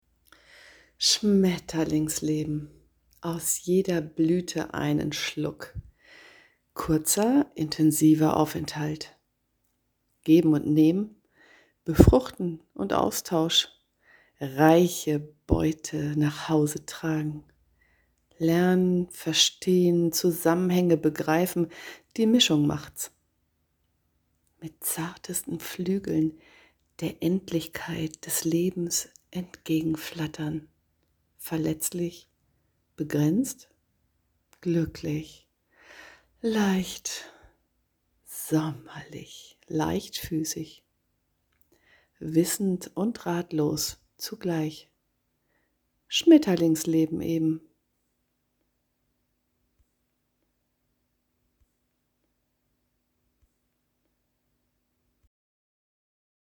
Die Hörbeiträge aus dem Tram